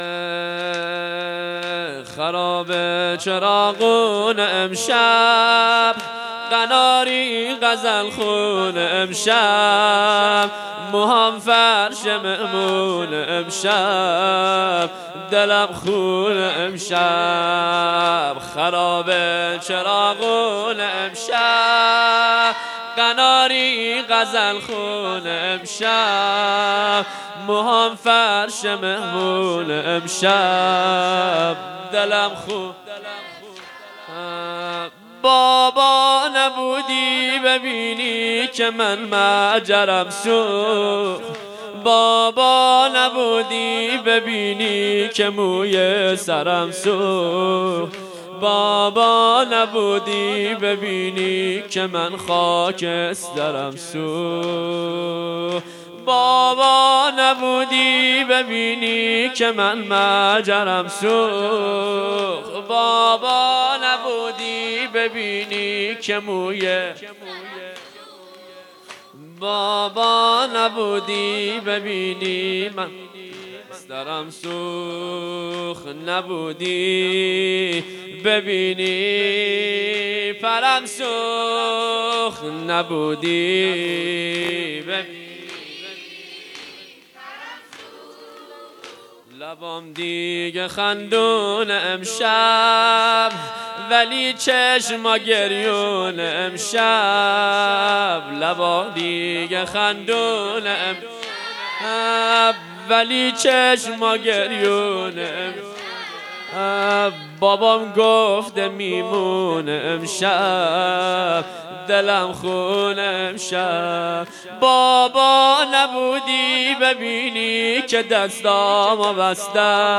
هیئت‌ هفتگی انصار سلاله النبی